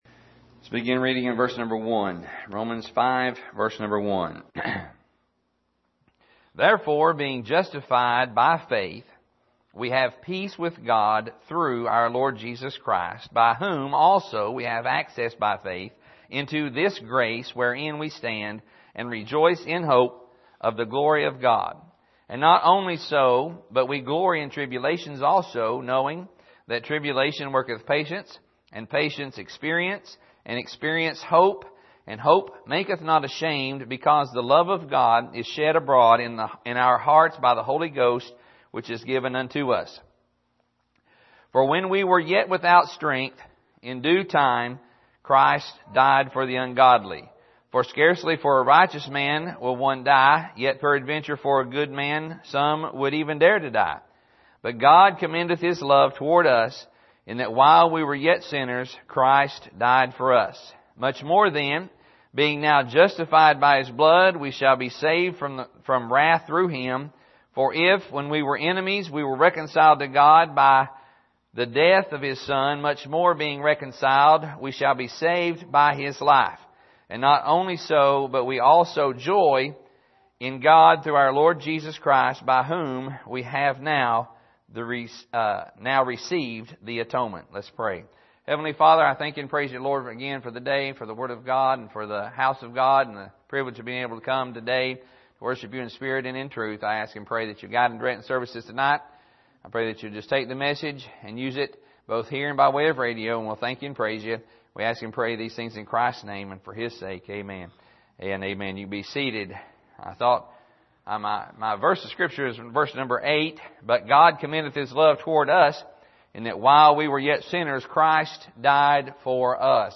Here is an archive of messages preached at the Island Ford Baptist Church.
Service: Sunday Morning